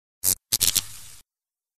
Звуки мерцания
Погрузитесь в мир загадочных аудиоэффектов.